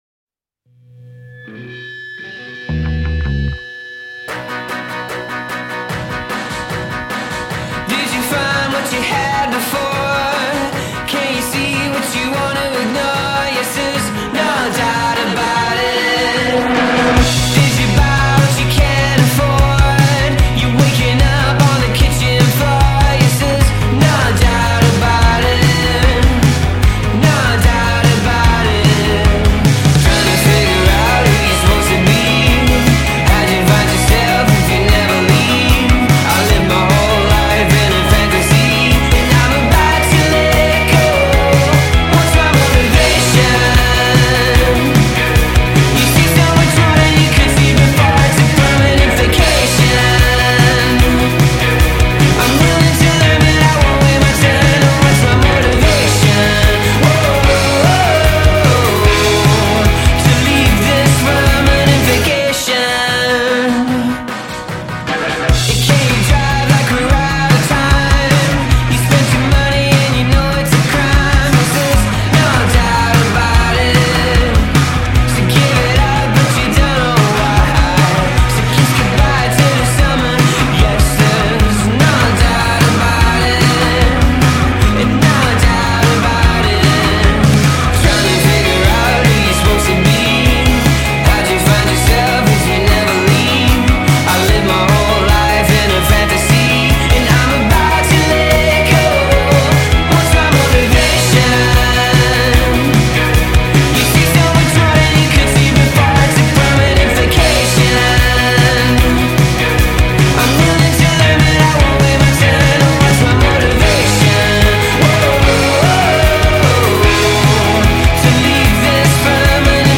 Irish indie rockers